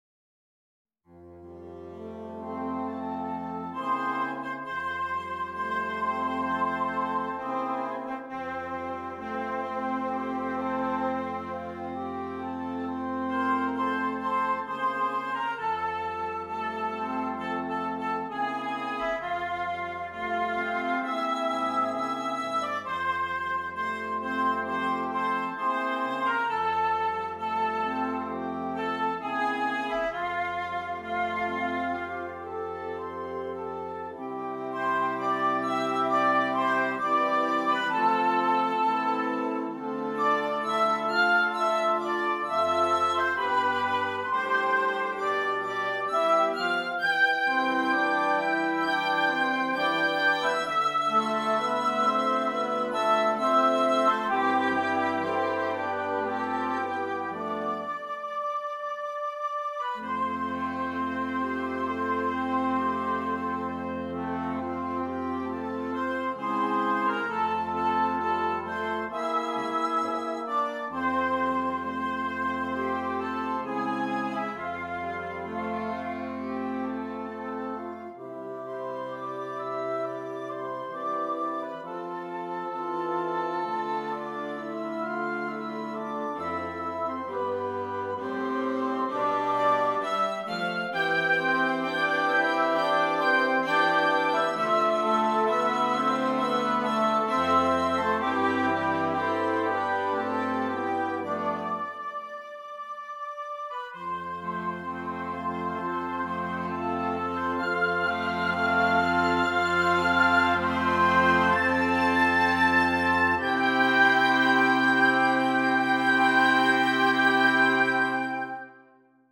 Interchangeable Woodwind Ensemble
PART 1 - Flute, Oboe, Clarinet
PART 4 - Clarinet, Alto Saxophone, F Horn
PART 5 - Clarinet, Alto Saxophone, Tenor Saxophone
PART 6 - Bass Clarinet, Bassoon, Baritone Saxophone